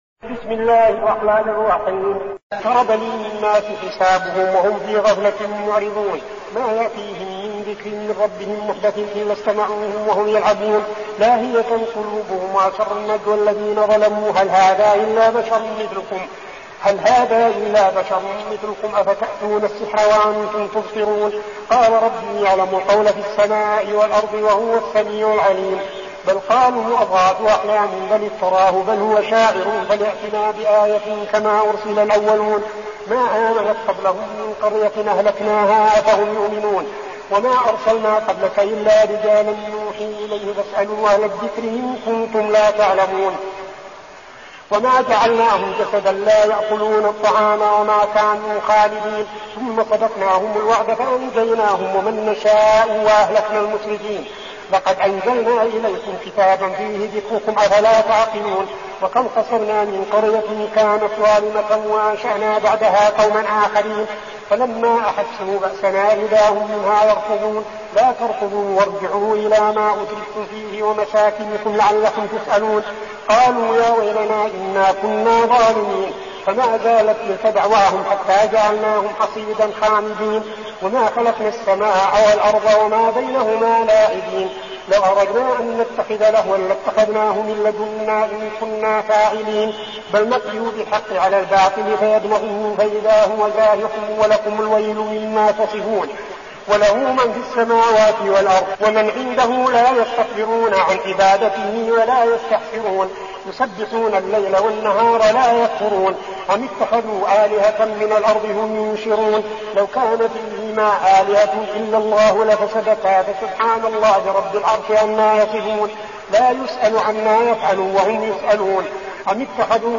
المكان: المسجد النبوي الشيخ: فضيلة الشيخ عبدالعزيز بن صالح فضيلة الشيخ عبدالعزيز بن صالح الأنبياء